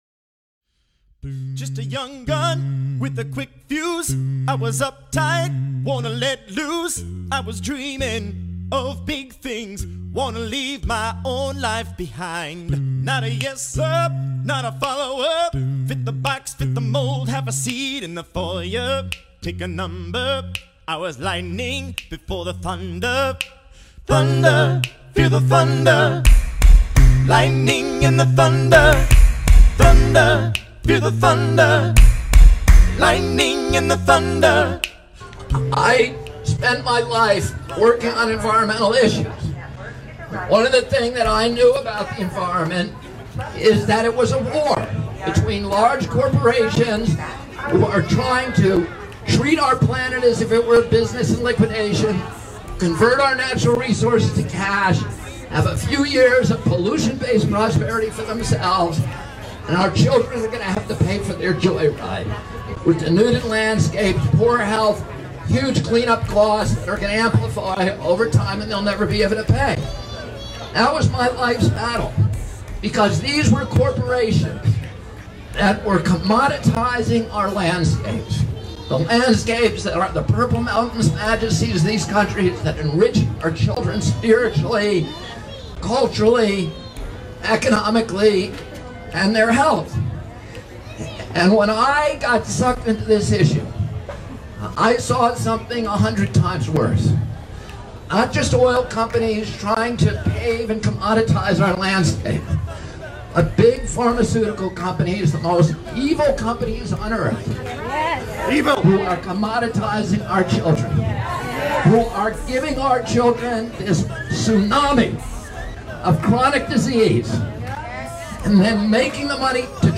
Robert F. Kennedy, Jr. at the Occupy Sacramento event - September 2019